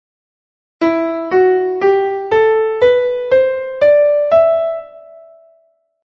What kind of scale are you listening to?